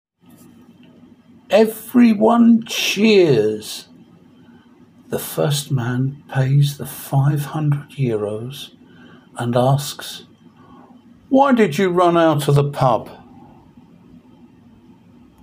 Each line is written in English and then in Spanish and has a recording of me reading it.